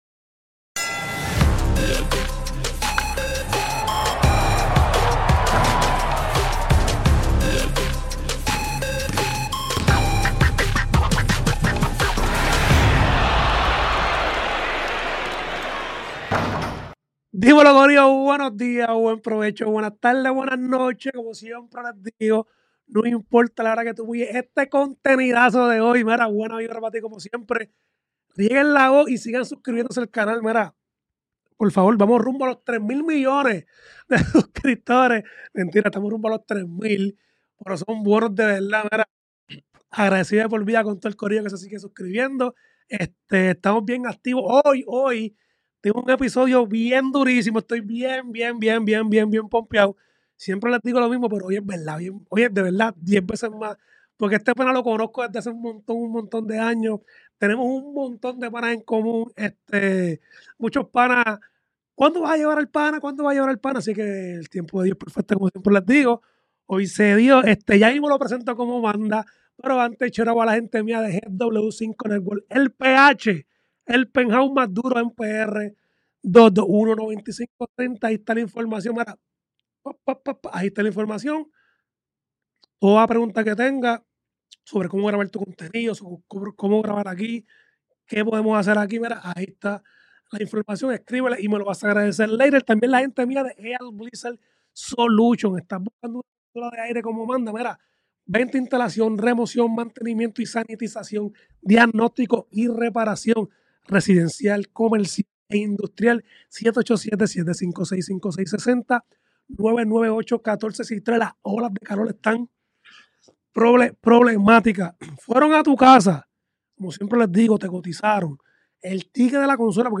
Grabado en GW-5 Studio